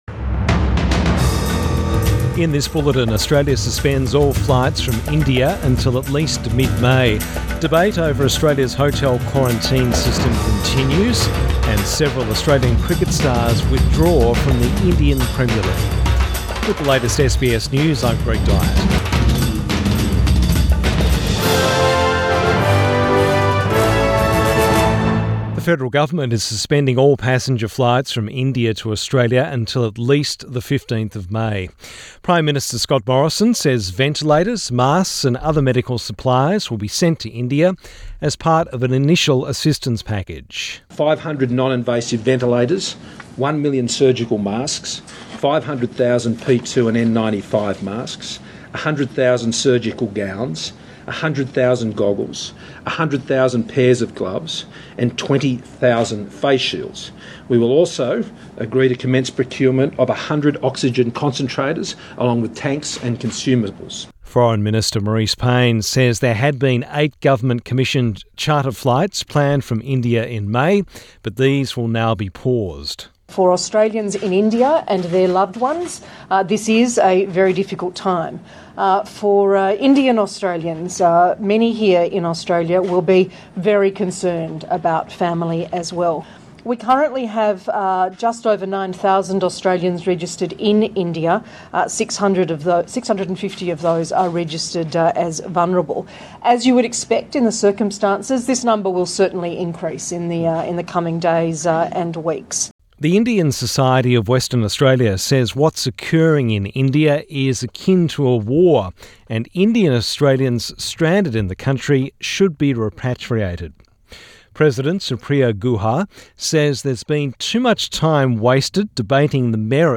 PM bulletin 27 April 2021